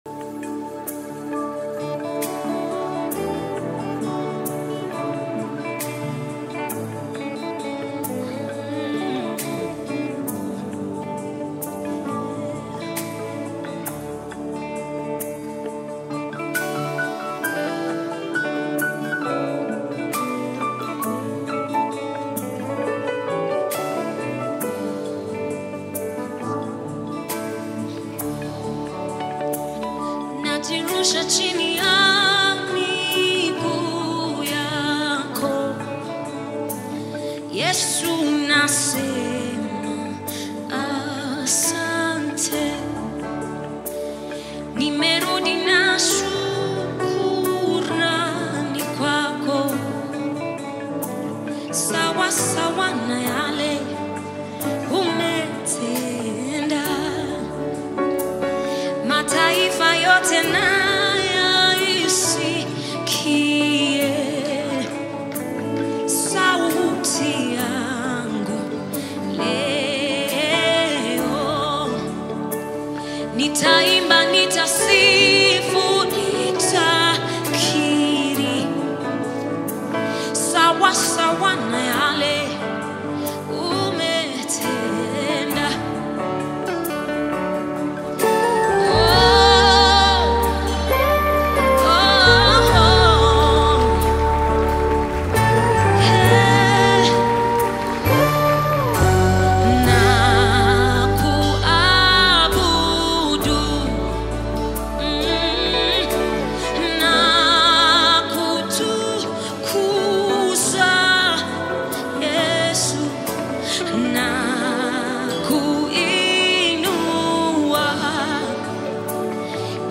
The passionate gospel singer
With its message and captivating melody